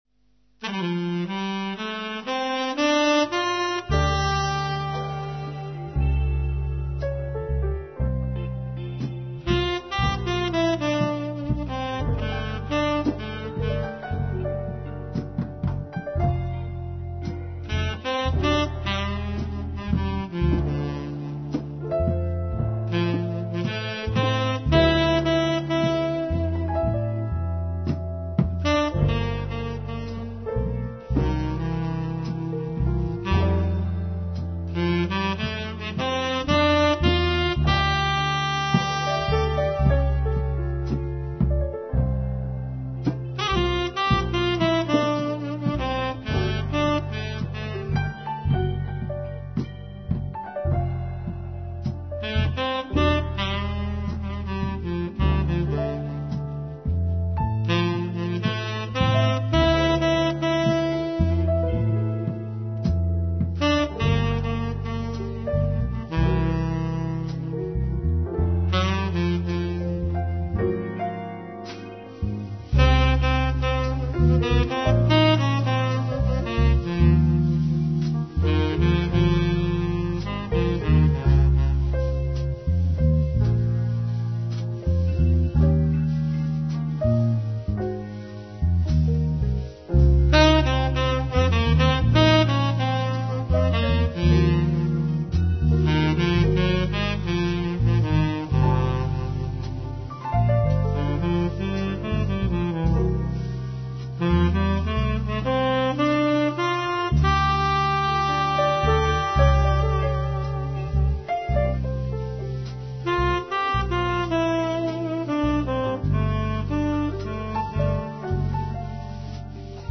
guitarist
jazz